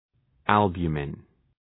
{æl’bju:mın}